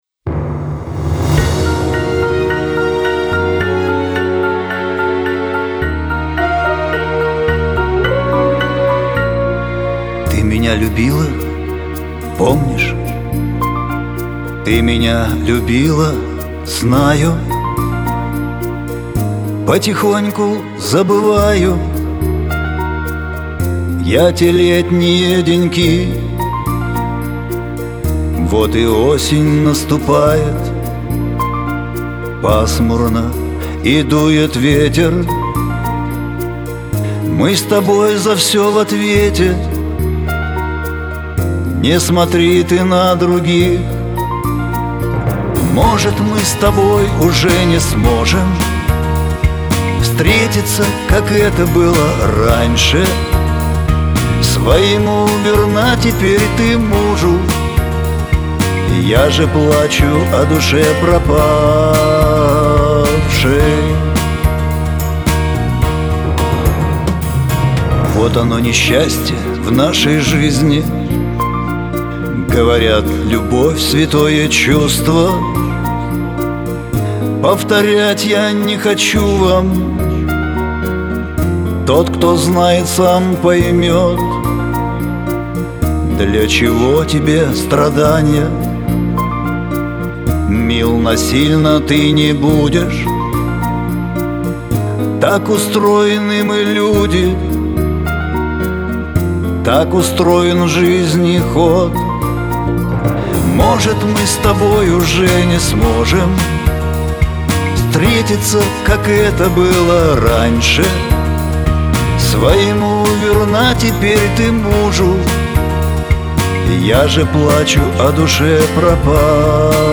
шансон new